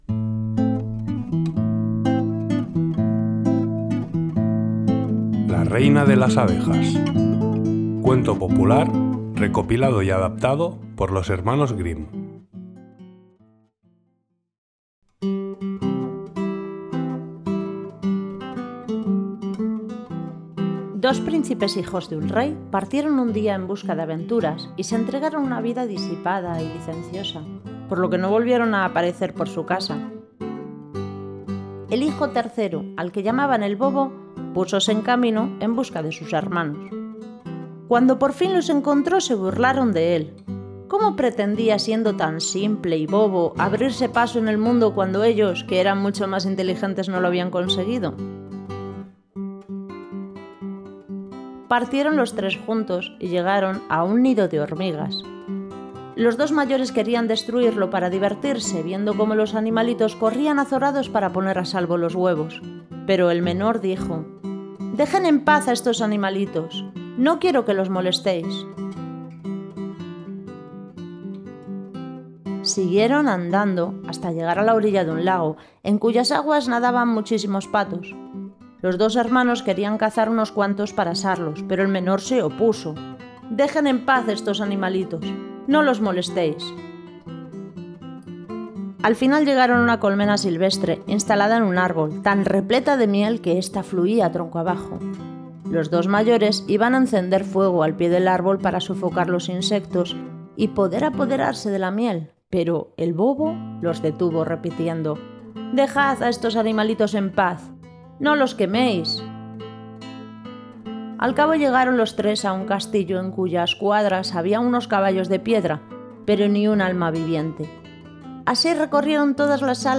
Cuento clásico de los Hermanos Grimm narrado en Español